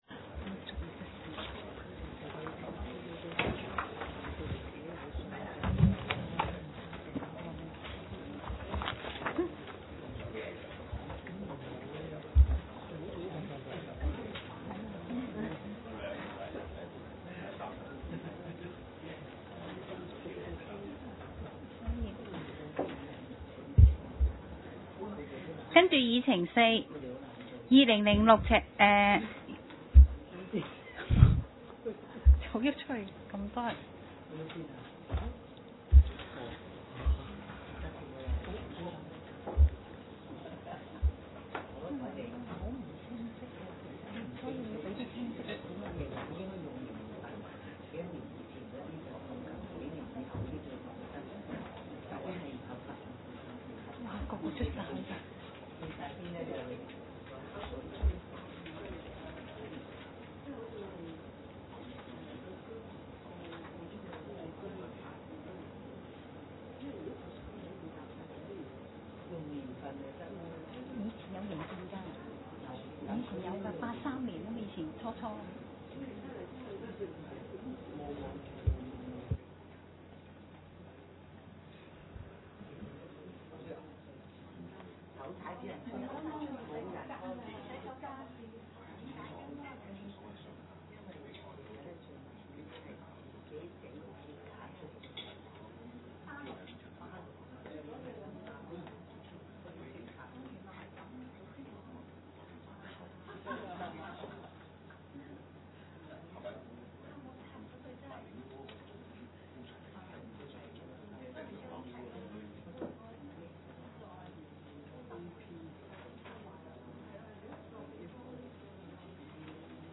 Agenda for the 19th Meeting of the
地點  ﹕ 香港 Venue : SDC Conference Room,